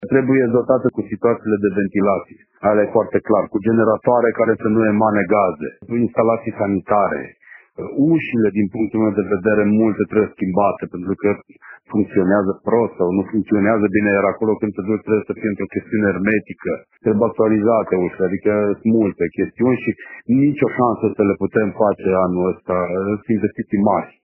Celelalte sunt ale asociațiilor de locatari, iar potrivit viceprimarului Cosmin Tabără, cele mai multe au nevoie de reparații masive: